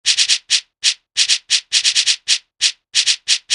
Shaker Loop 135bpm.wav